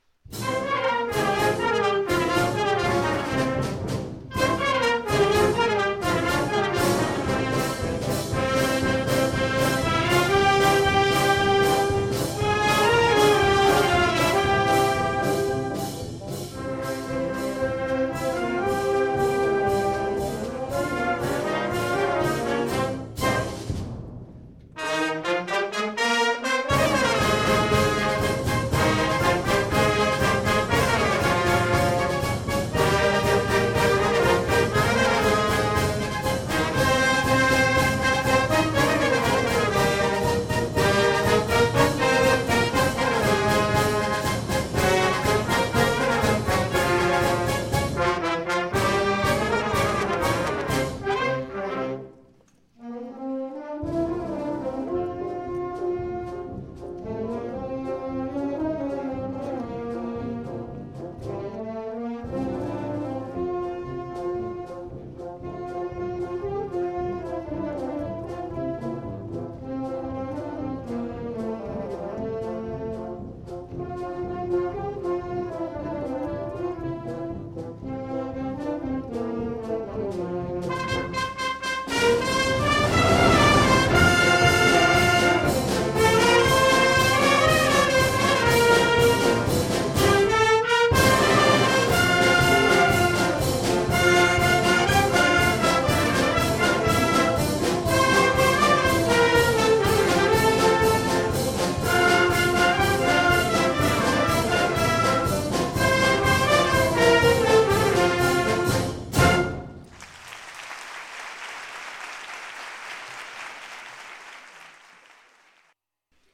性別： 2ステップ